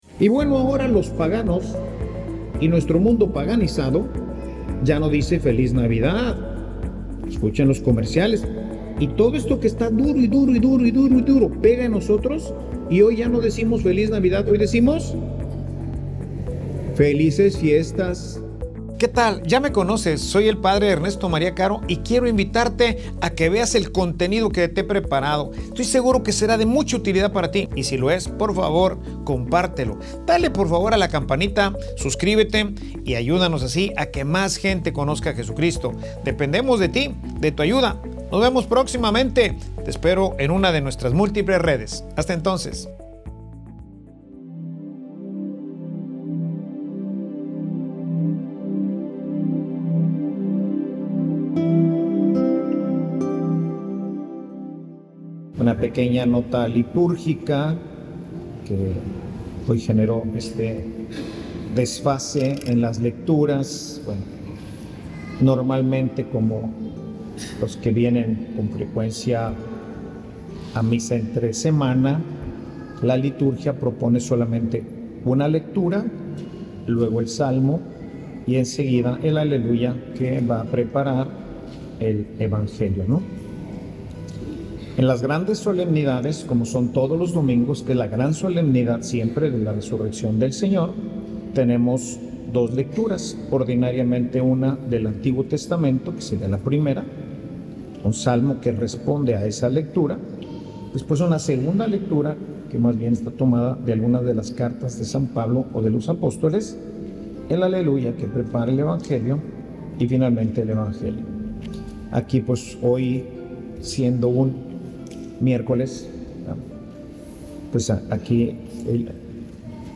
Homilia_La_palabra_te_hara_inmensamente_feliz.mp3